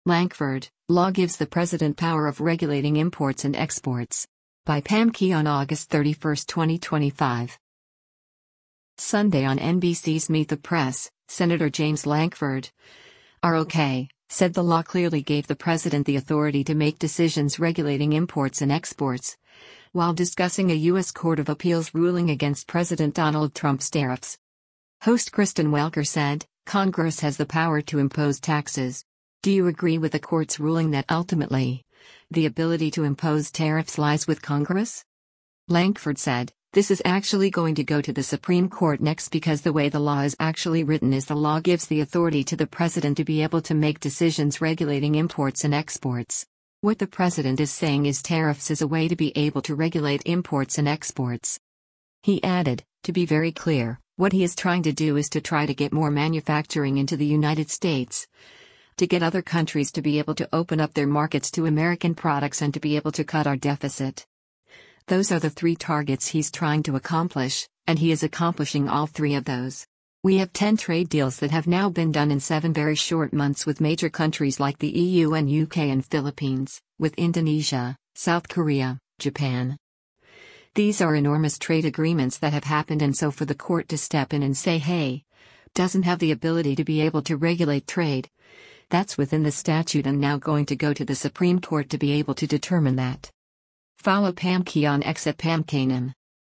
Sunday on NBC’s “Meet the Press,” Sen. James Lankford (R-OK) said the law clearly gave the president the authority to make decisions “regulating imports and exports,” while discussing a U.S. Court of Appeals ruling against President Donald Trump’s tariffs.